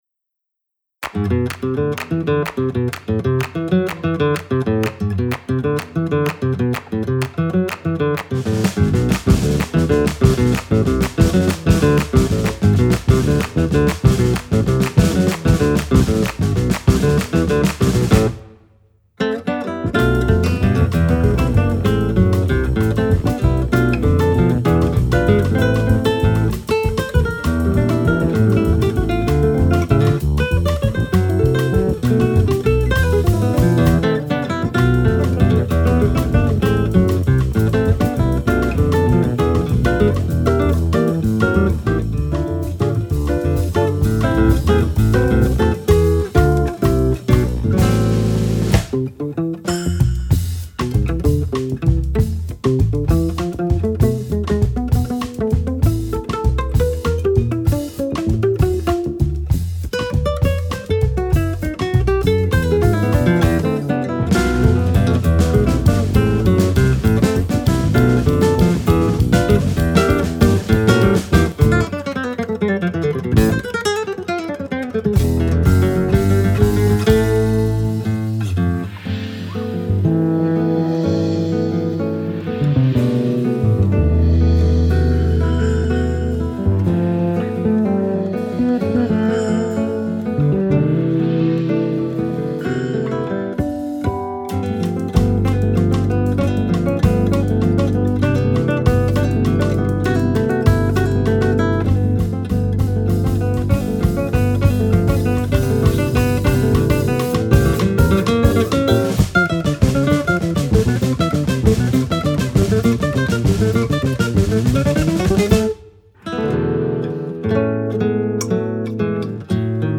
batería
bajo
fue grabado en vivo